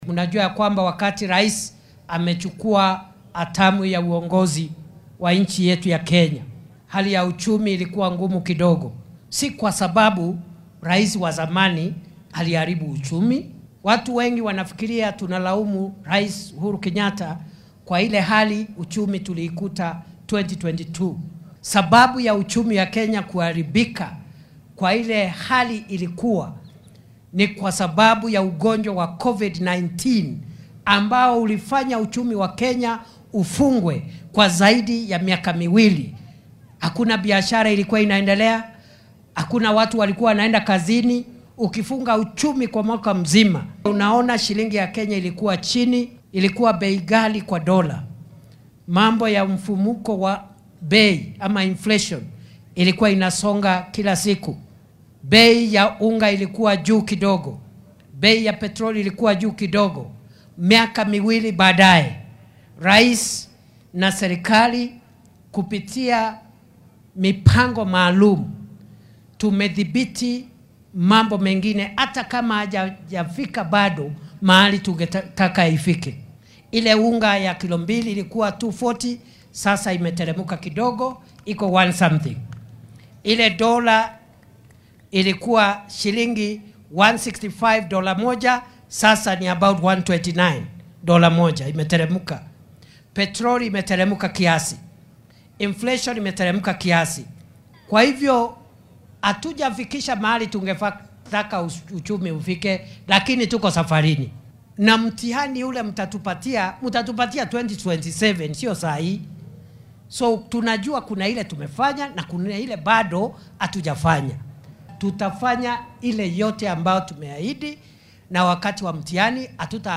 Xilli uu munaasabad kaniiseed uga qayb galay ismaamulka Kajiado ayuu Kindiki sheegay in kastoo dimuqraadiyadda ay dhiirrigelinaysa xurriyadda in qofka uu dareenkiisa cabbiro haddana in aan loo baahnayn in ay waxyeelleyso wadajirka kenyaanka.